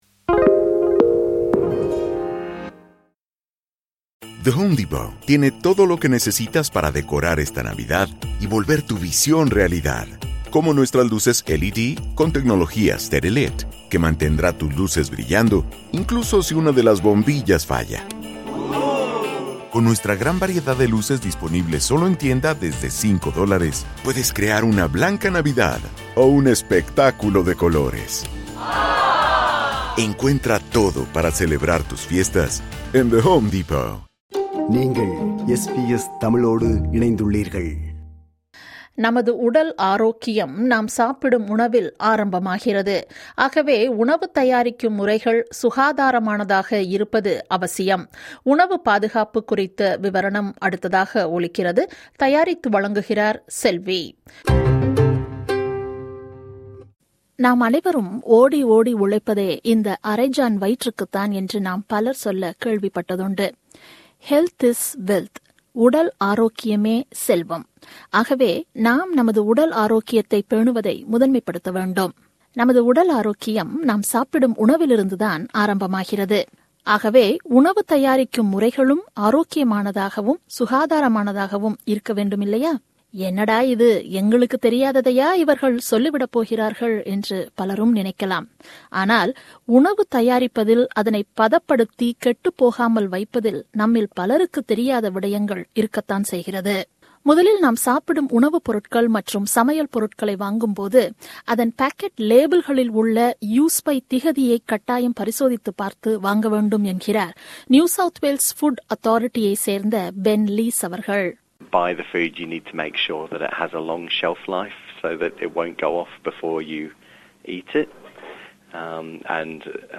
நமது உடல் ஆரோக்கியம் நாம் சாப்பிடும் உணவில் ஆரம்பமாகிறது ஆகவே உணவு தயாரிக்கும் முறைகள் சுகாதாரமானதாக இருப்பது அவசியம். உணவு பாதுகாப்பு குறித்த விவரணம்